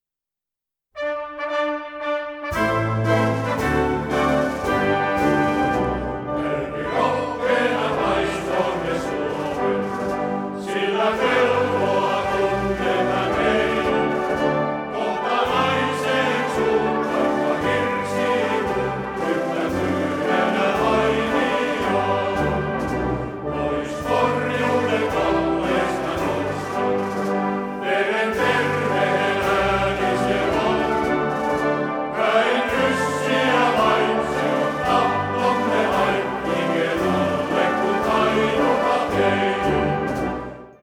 Kuoron lauluohjelmisto koostuu mieskuoro-ohjelmiston lisäksi myös erilaisista ja erimaalaisista sotilaslauluista , hengellisistä lauluista ja seranadeista.